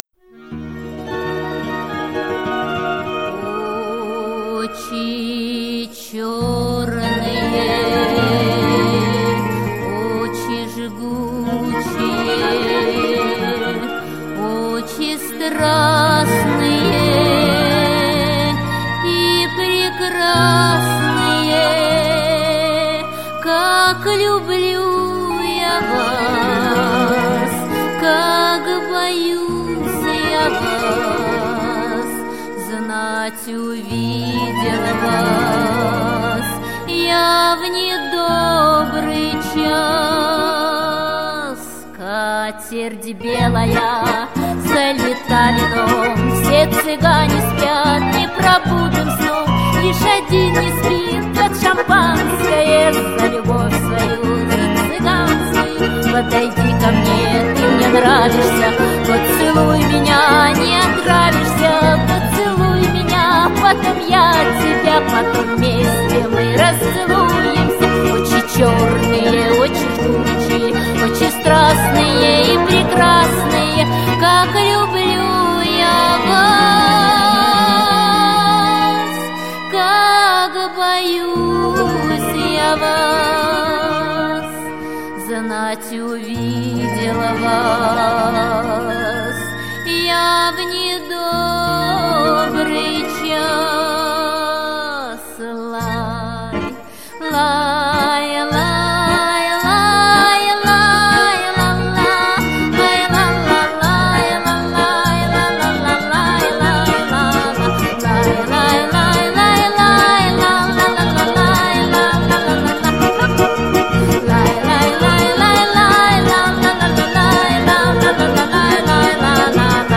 Жанр: Поп-фолк